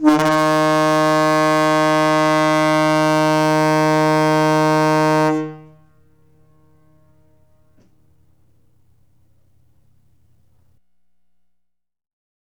Index of /90_sSampleCDs/E-MU Producer Series Vol. 3 – Hollywood Sound Effects/Water/Tugboat Horns
TUGBOATHO00L.wav